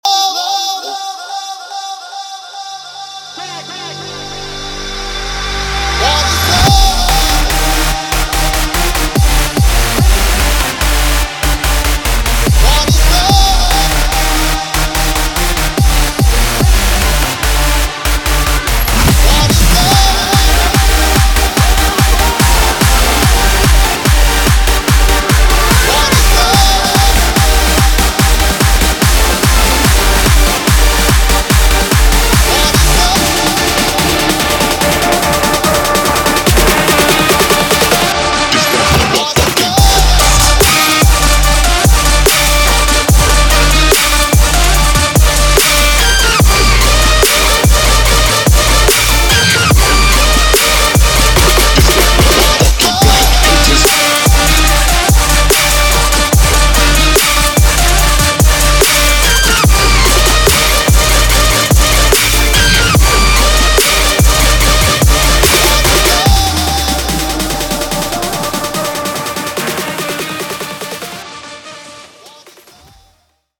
• Trance
• Dubstep
• EDM